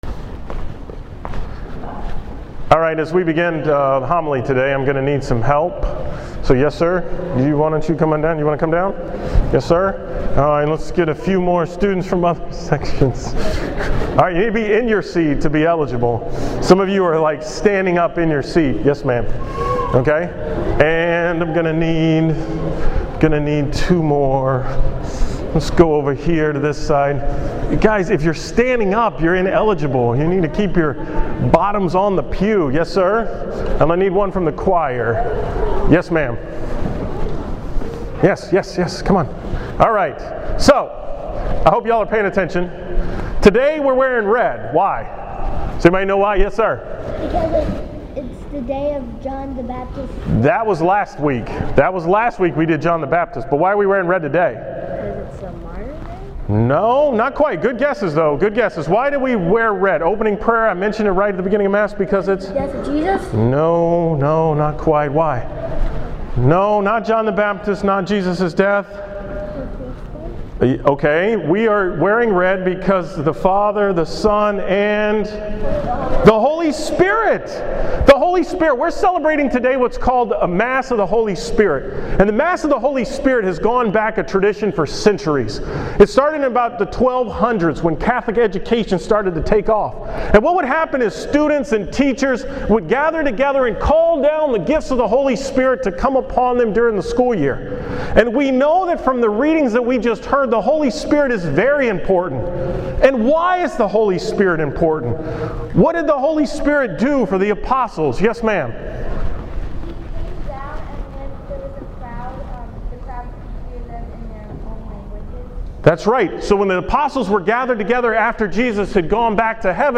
The 'Opening School Mass' on Friday, September 6th.